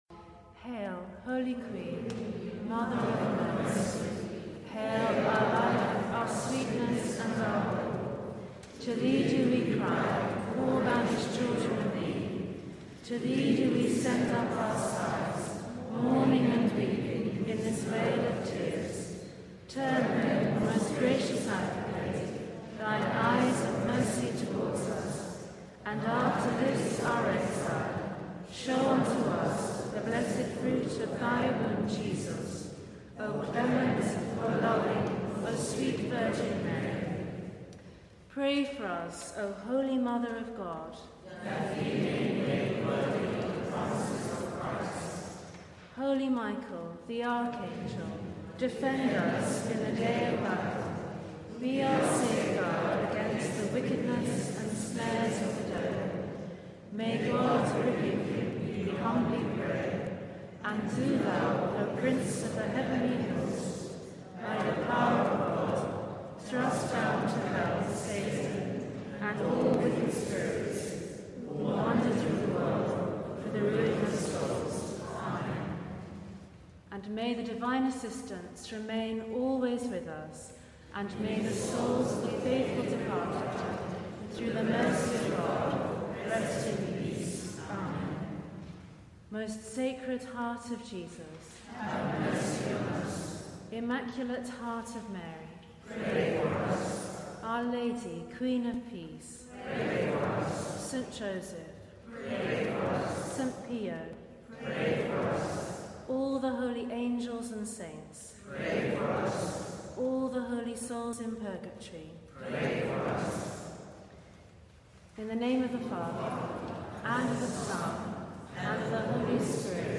The Rosary with Westminster Cathedral Rosary Group - Totus2us
Prayers at the end of the sorrowful mysteries